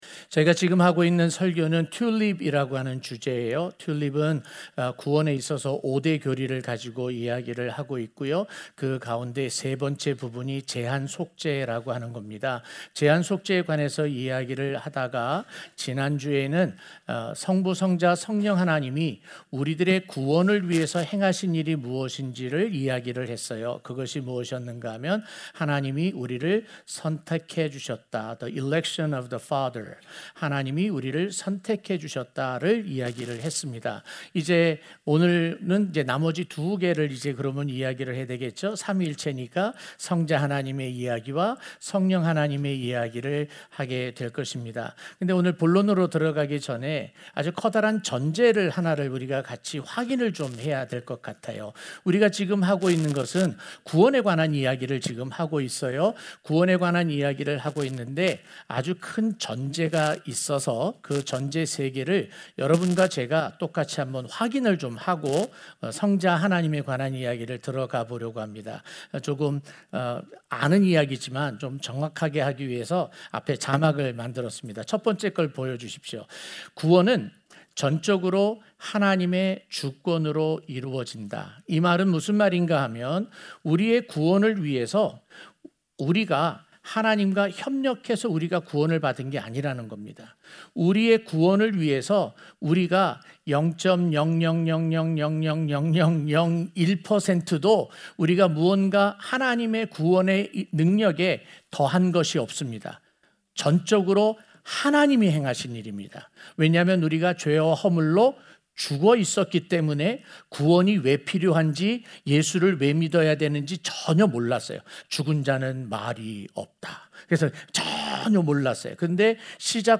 주일설교
03-C-Limited-Atonement-3-Sunday-Morning.mp3